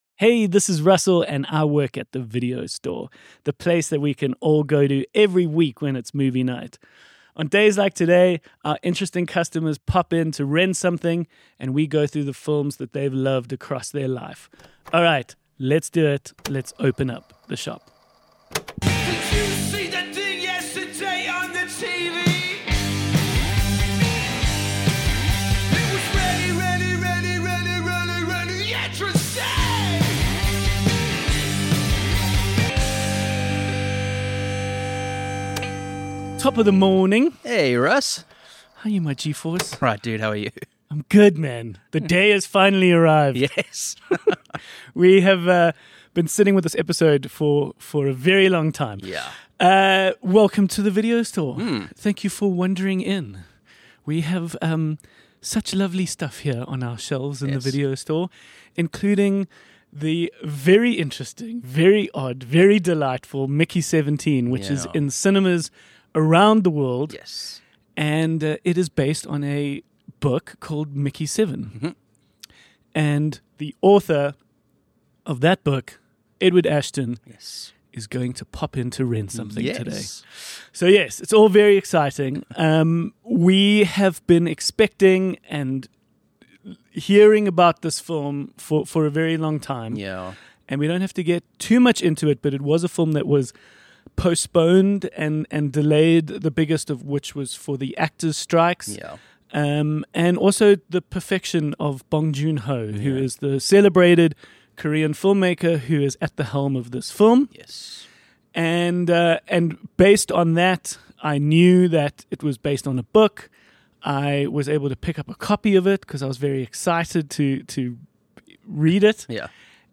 A weekly chat amongst friends working a shift at your local video store.